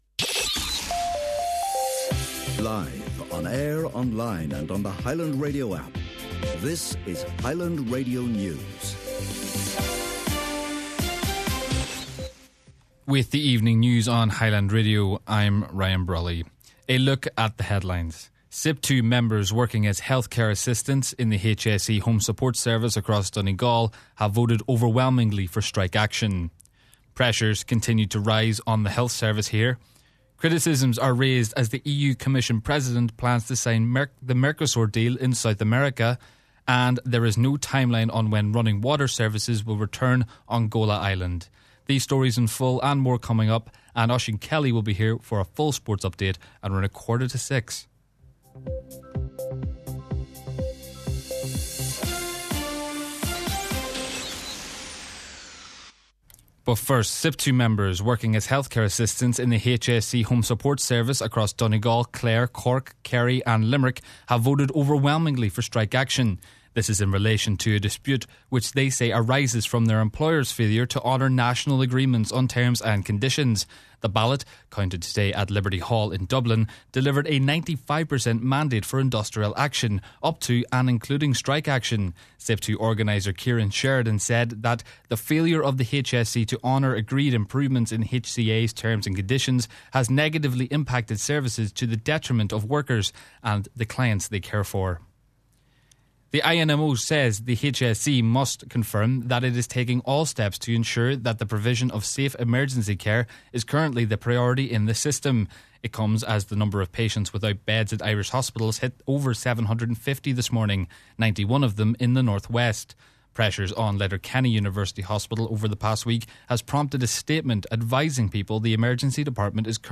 Main Evening News, Sport and Obituary Notices – Monday 12th January